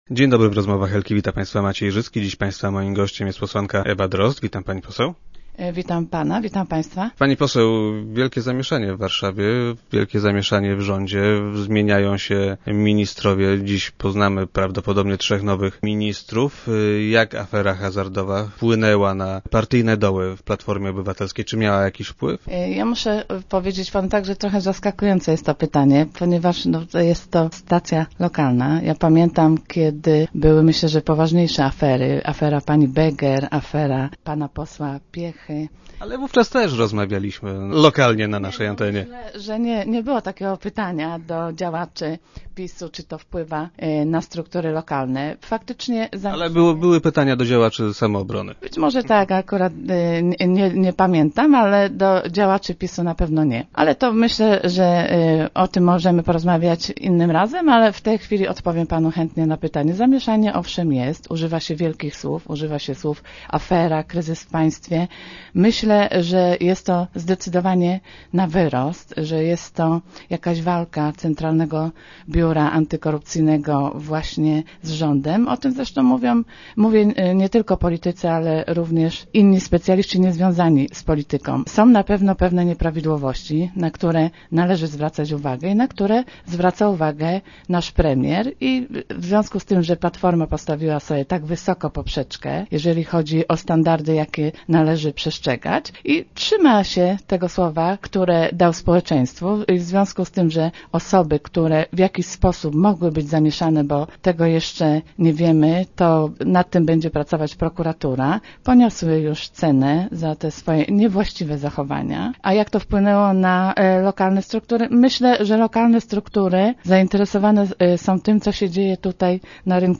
- W regionach robimy swoje - powiedziała parlamentarzystka, która była dziś gościem Rozmów Elki.